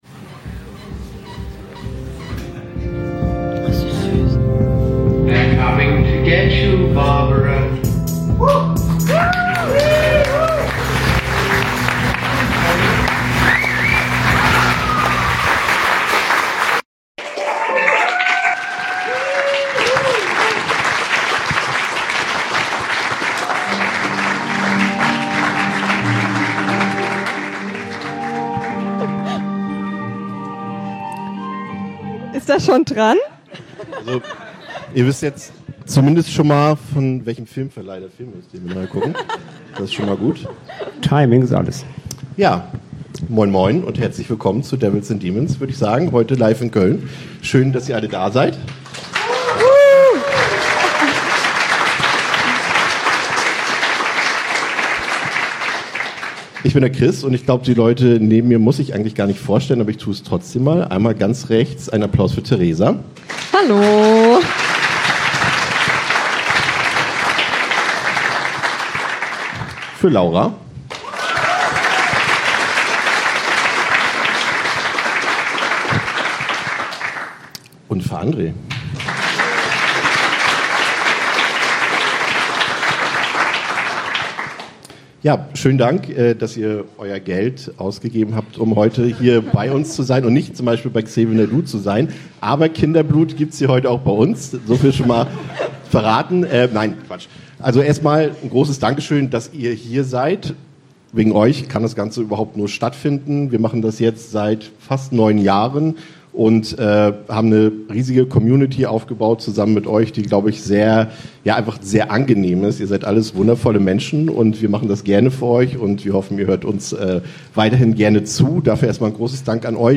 Beschreibung vor 3 Monaten Kurz vor Weihnachten haben wir im Turistarama-Kino in Köln unsere erste, komplett eigene Live-Show gespielt. Es gab einen Live-Podcast zum Thema Weihnachtshorrorfilme, ein Screening von Evil Dead II und anschließend die dritte offizielle Horrorfilm-Quiz-Weltmeisterschaft. Falls ihr keine Lust auf YouTube habt, könnt ihr den Podcast- und Quizpart hier nachhören.